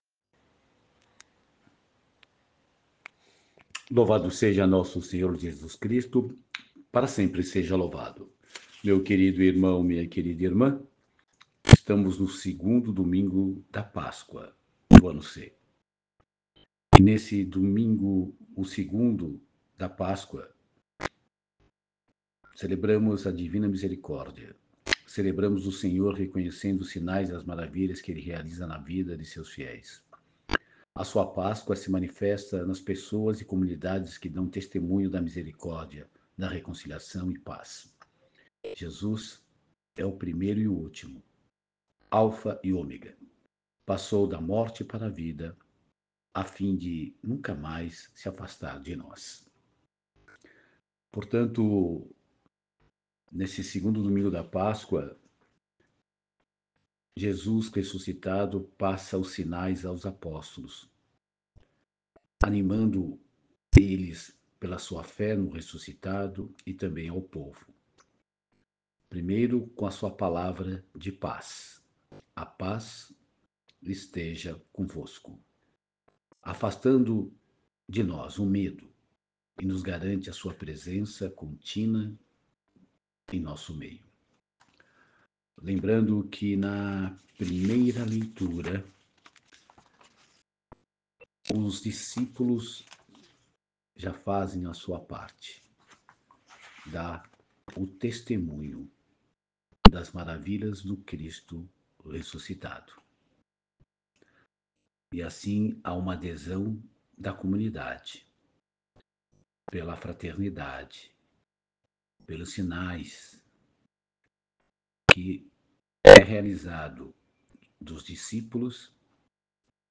Meditação e Reflexão do 2 Domingo da Páscoa. Ano C